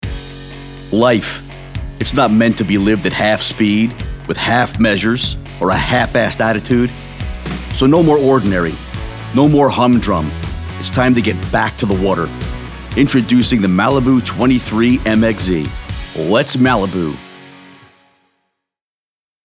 announcer, attitude, bravado, edgy, hard-sell, inspirational, motivational, serious